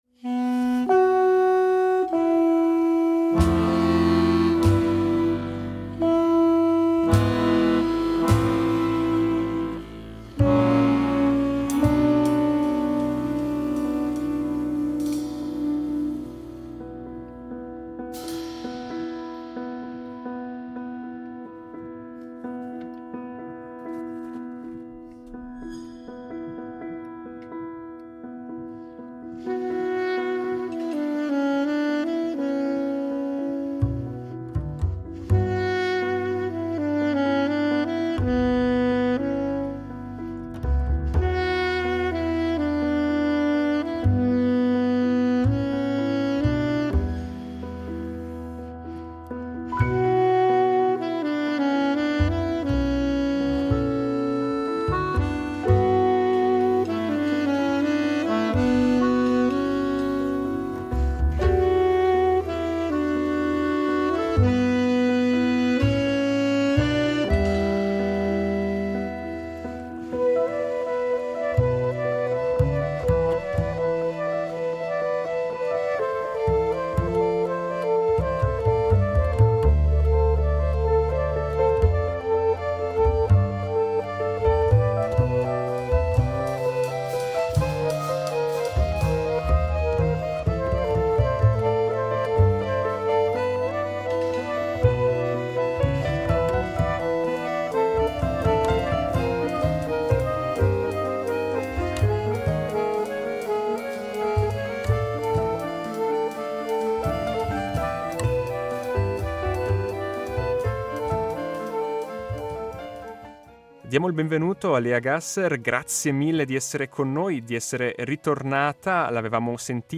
Possiamo ascoltare brani che possiamo sentire in quest’intervista.